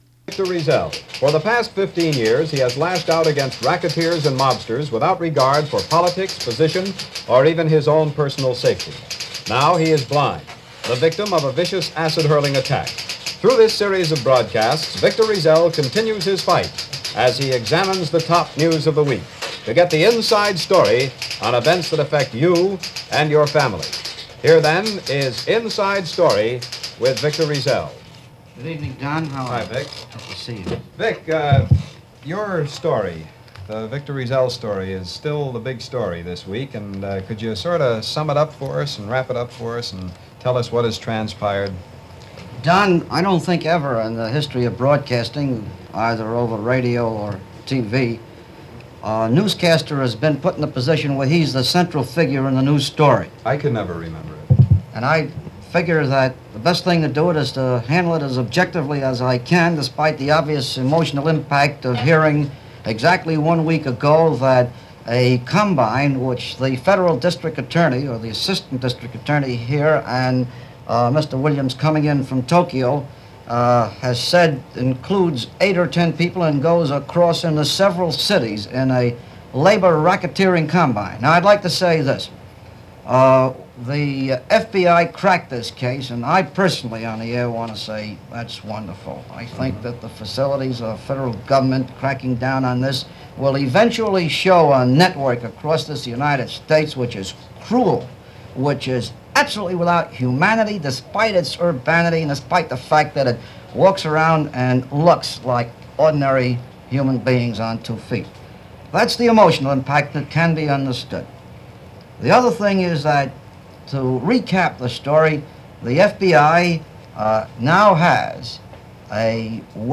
Beginning shortly after the attack on Riesel, NBC Radio ran a series of broadcasts featuring Victor Riesel with Labor news of the previous week. Here is that broadcast, Inside Story featuring Victor Riesel, from August 24, 1956.